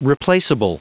Prononciation du mot replaceable en anglais (fichier audio)
Prononciation du mot : replaceable